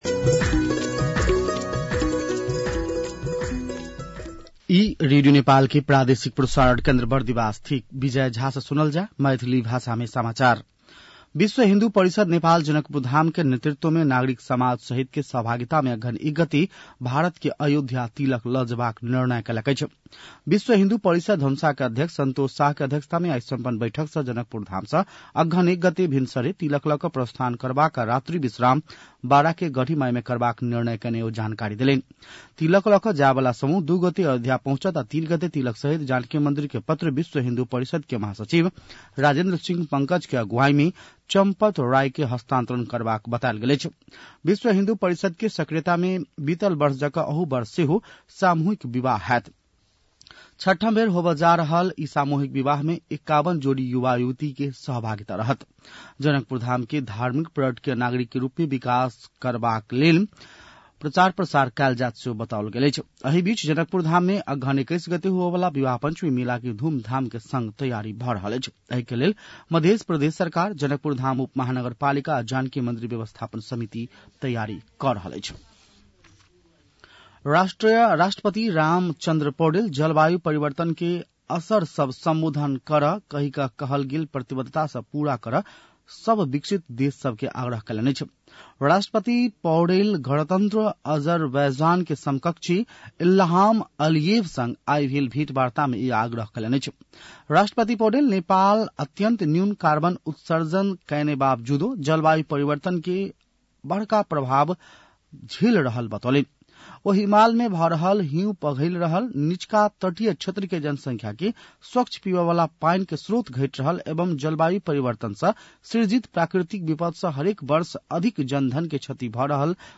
मैथिली भाषामा समाचार : ३० कार्तिक , २०८१
Maithali-news-7-29.mp3